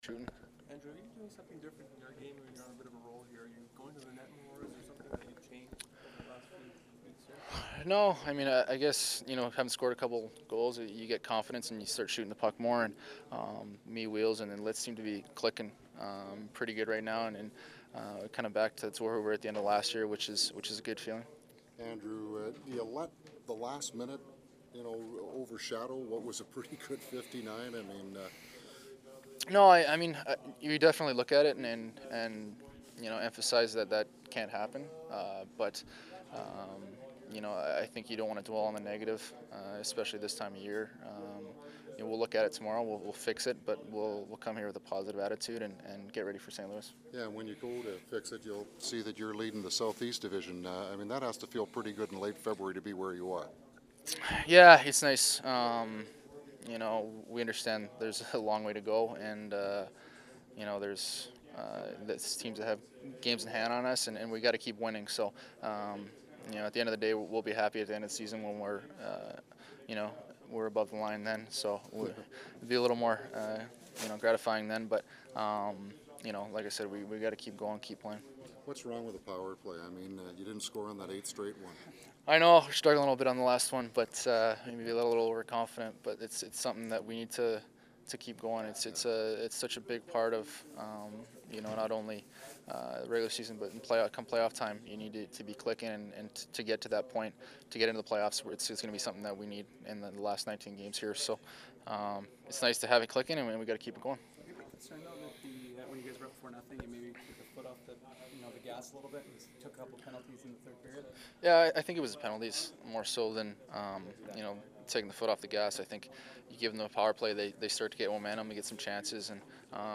Winnipeg Jets post-game audio – Illegal Curve Hockey